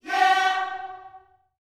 YEAH F#4A.wav